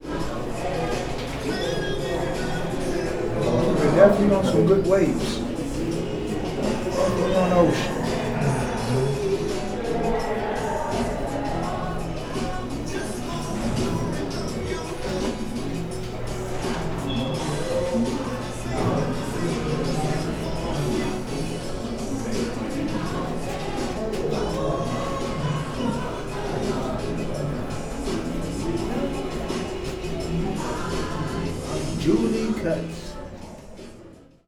Sound file 1.8 The sound of the barbershop in a dormitory unit